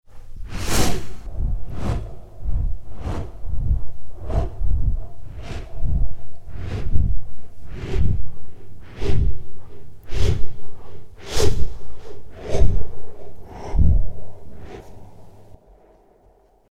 Âm thanh tiếng Rồng vỗ cánh